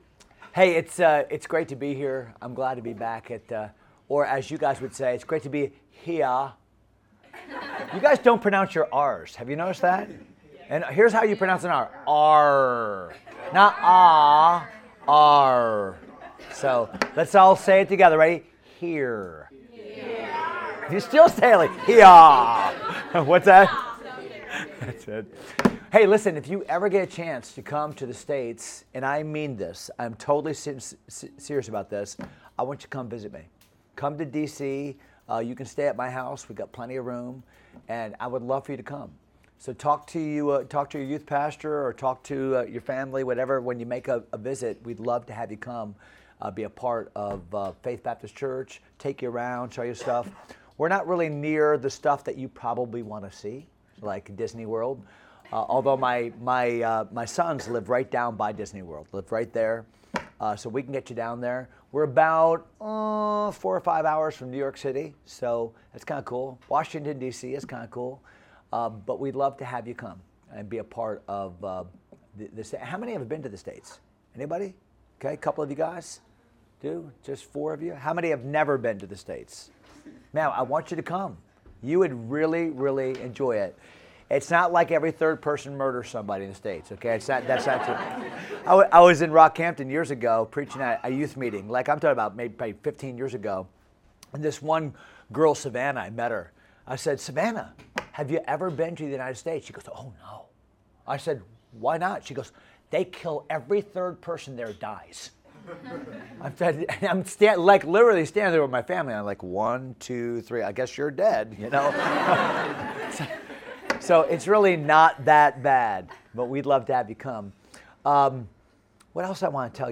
Sermons | Good Shepherd Baptist Church
Leadership Conference 2024